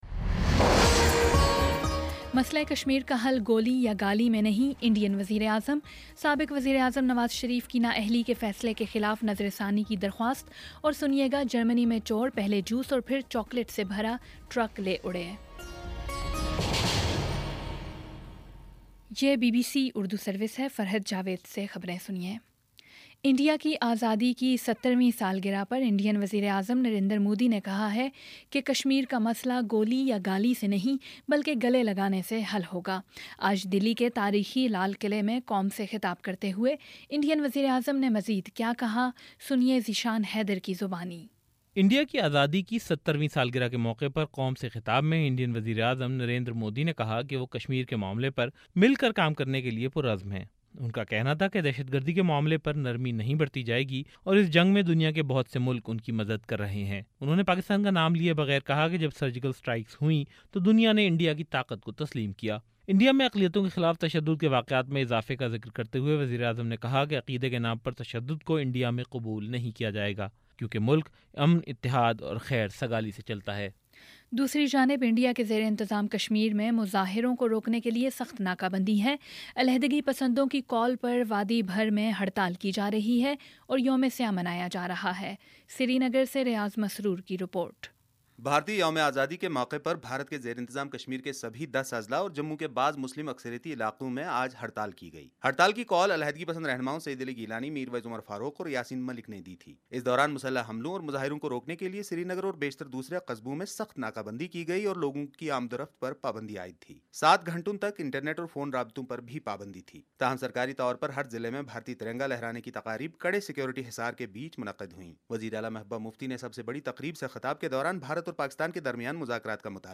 اگست15 : شام سات بجے کا نیوز بُلیٹن